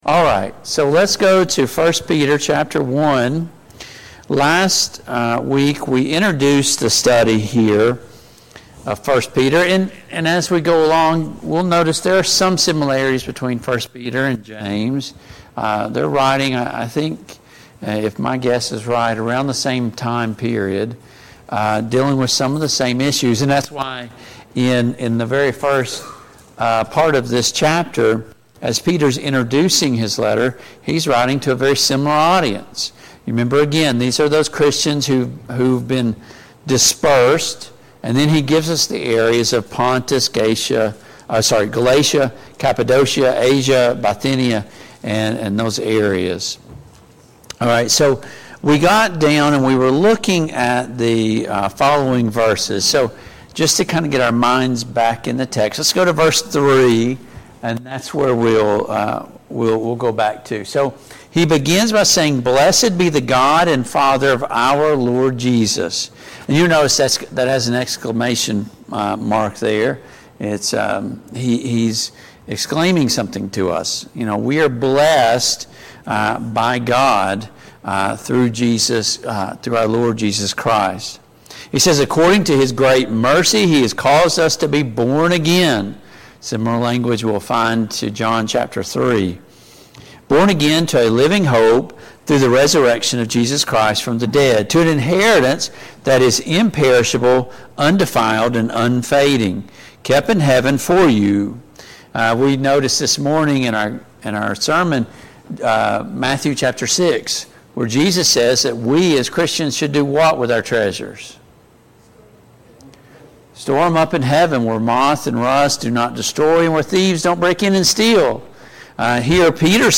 Study of James and 1 Peter and 2 Peter Passage: 1 Peter 1:1-8 Service Type: Family Bible Hour « Does it matter where and when I worship?